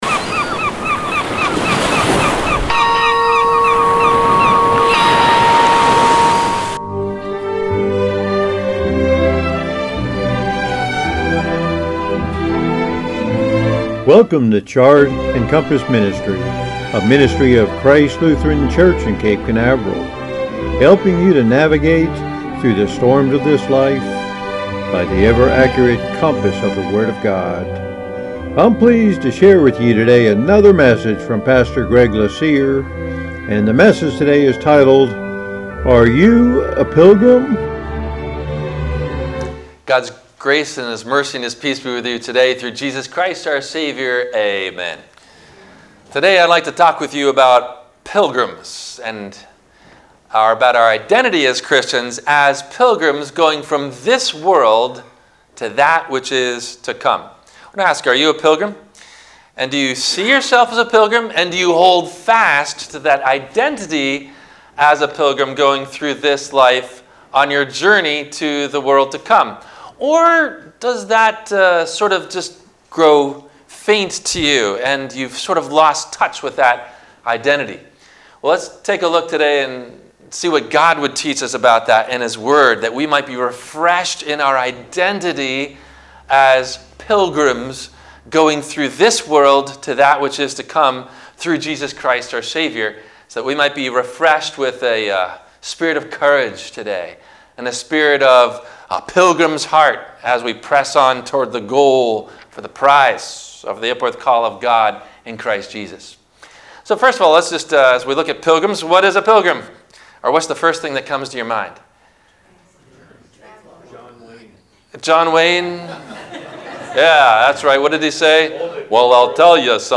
Are You A Pilgrim? – WMIE Radio Sermon – March 29 2021
WMIE Radio – Christ Lutheran Church, Cape Canaveral on Mondays from 12:30 – 1:00